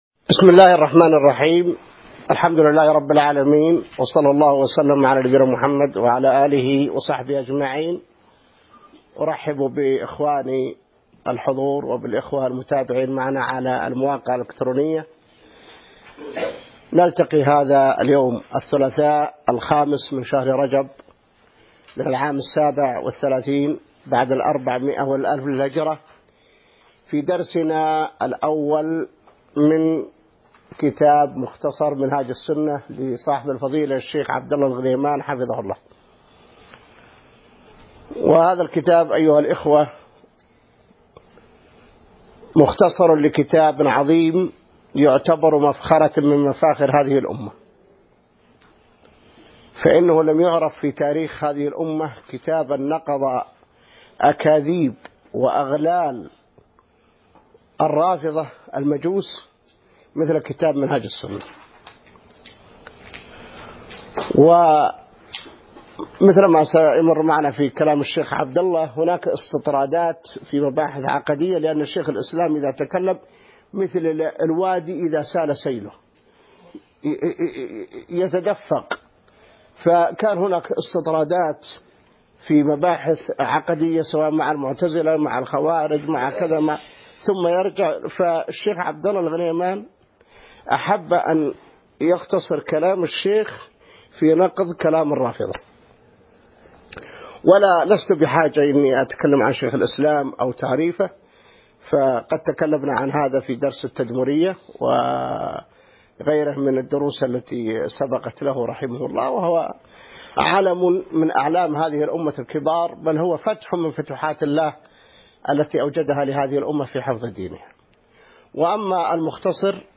الدرس الأول من شرح مختصر منهاج السنة | موقع المسلم